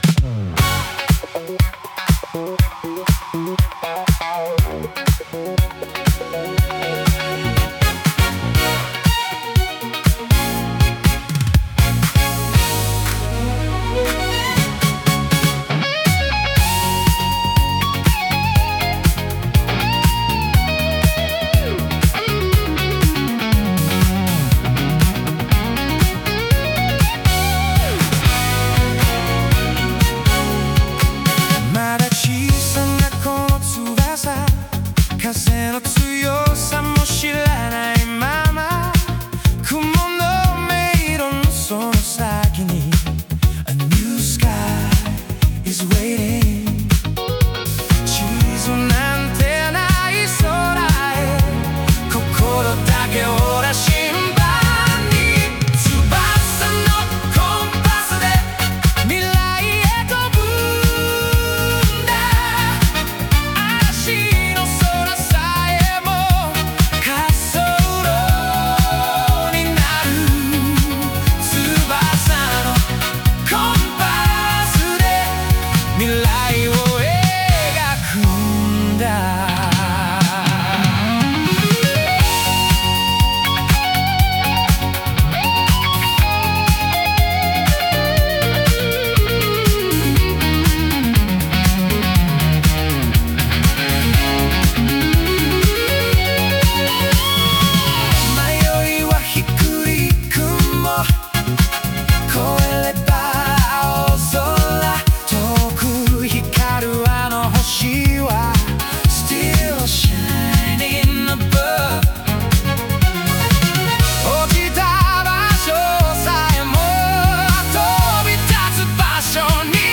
男性ボーカル
イメージ：1970年代,男性ボーカル,ファンクディスコ,J-ポップ,爽やか